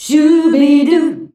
SCHUBIDU E.wav